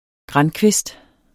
Udtale [ ˈgʁɑn- ]